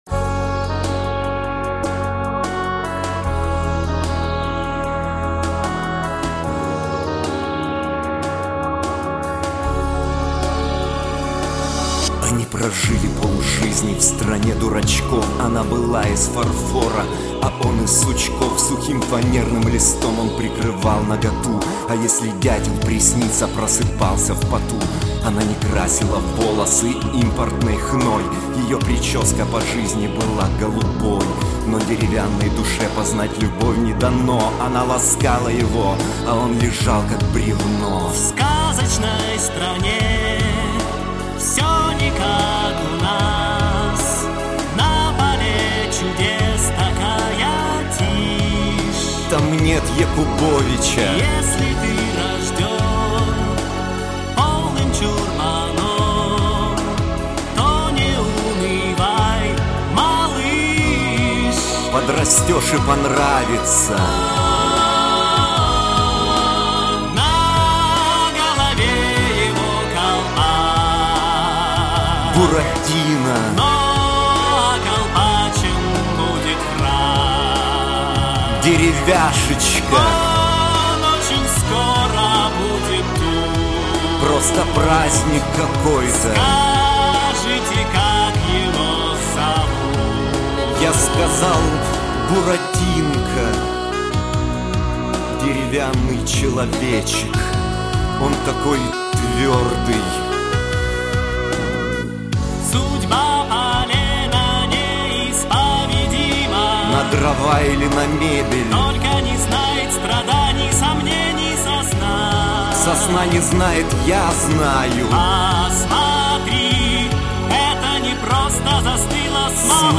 Ржачная песенка:)